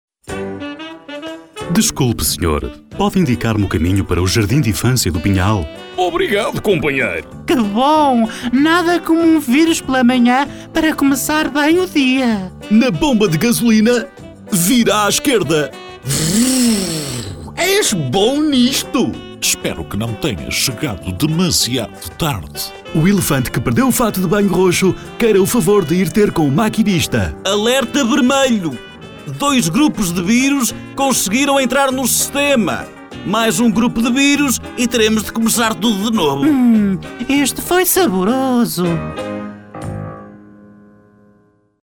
Portugiesischer Sprecher.
Sprecher portugiesisch.
Sprechprobe: eLearning (Muttersprache):